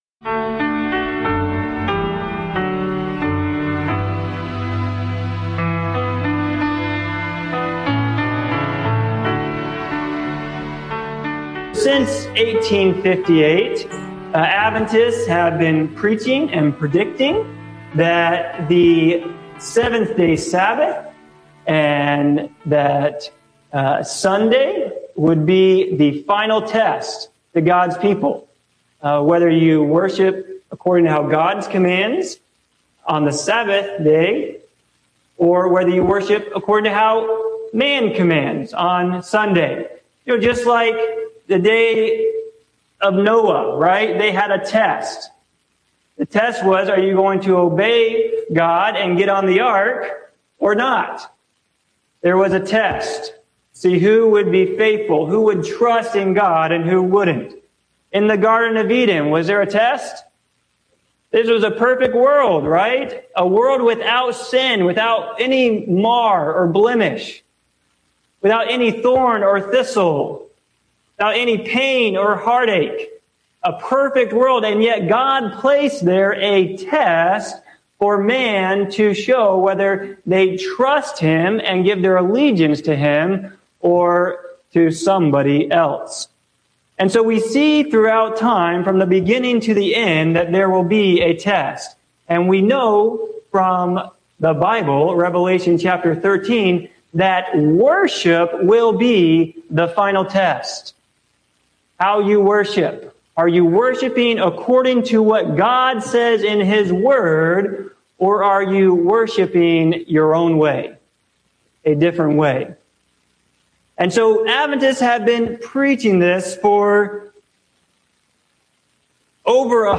This sermon explores the historical, cultural, and prophetic foundations of the Seventh-day Adventist understanding of the Sabbath and end-time events.